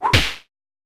CartoonPunch
Cartoon Foley Punch sound effect free sound royalty free Movies & TV